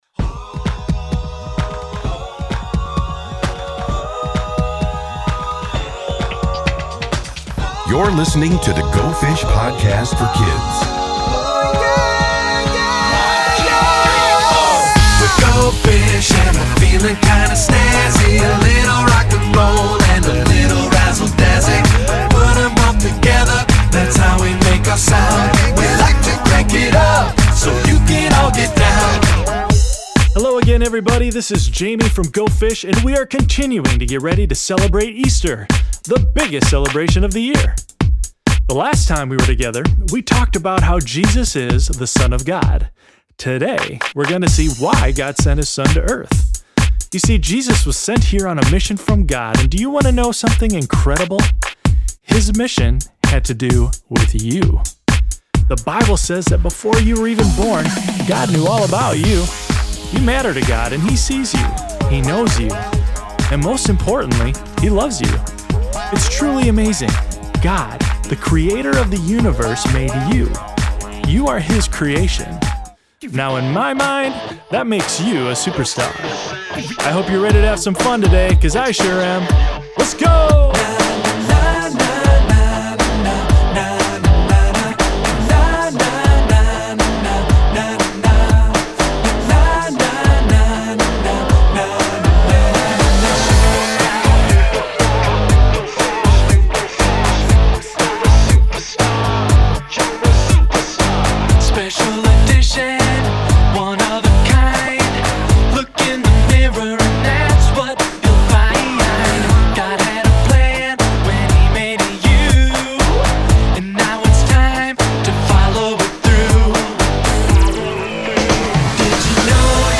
Go Fish uses music and Scripture to get children ready to celebrate Easter!